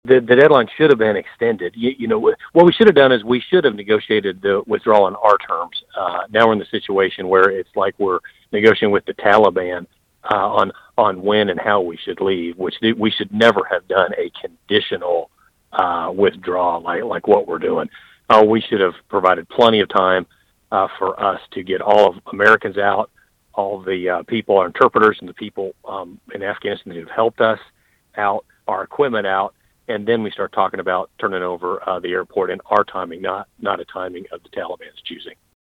Speaking with KVOE News on Monday, Mann echoed issues outlined by other Republicans earlier this month about the withdrawal process, including the planning and what he says is our reliance on the Taliban to ensure a relatively smooth process.